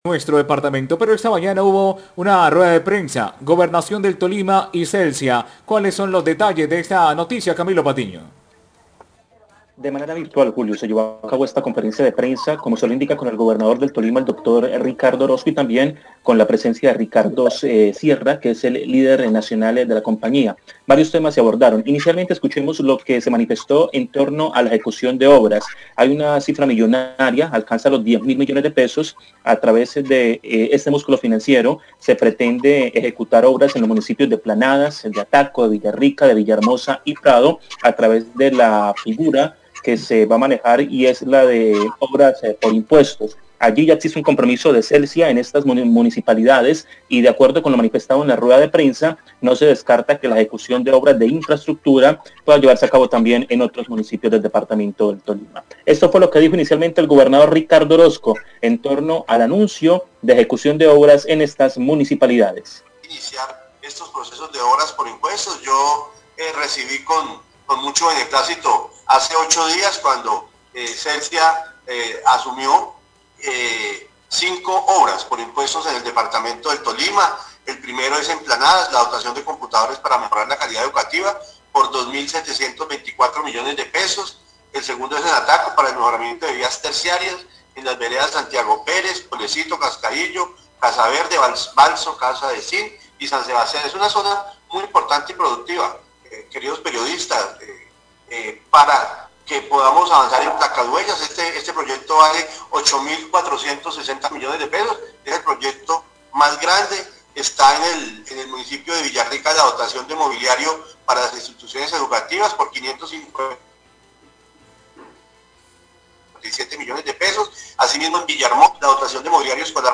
Rueda de prensa virtual entre Celsia y Gobernación del Tolima dio buenas noticias a los tolimenses
Radio